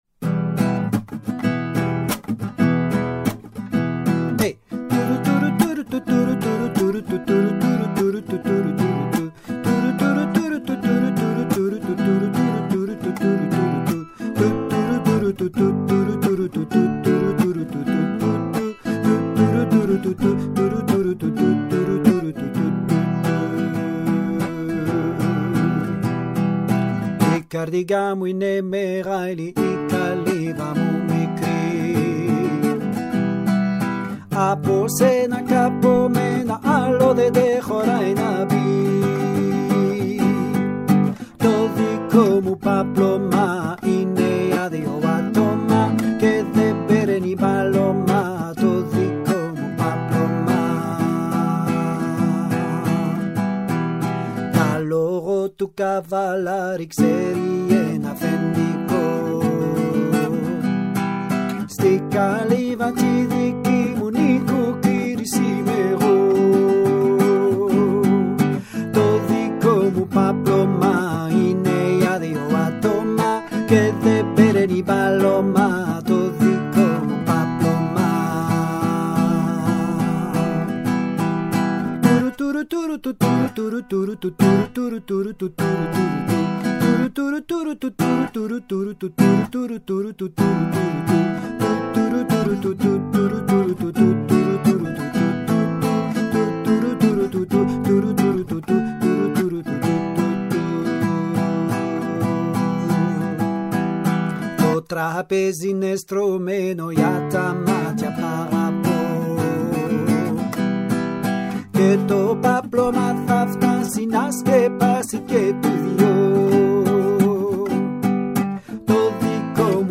Voix 01 :